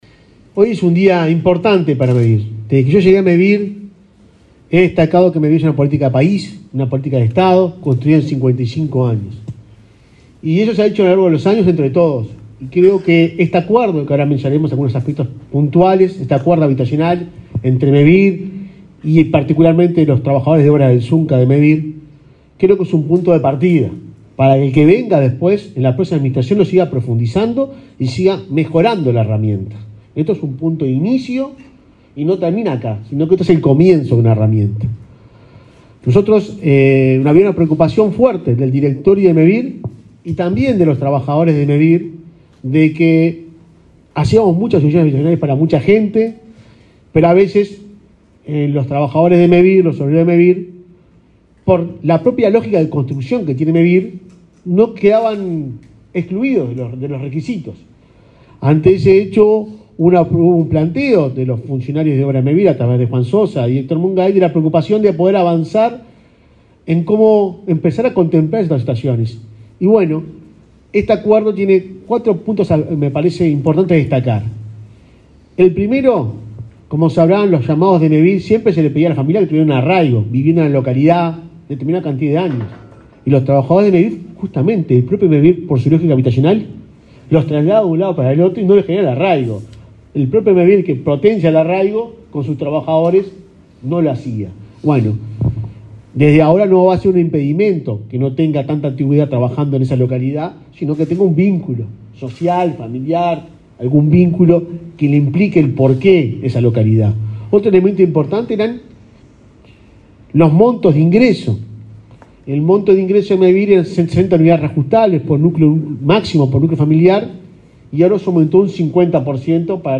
Palabras del presidente de Mevir, Juan Pablo Delgado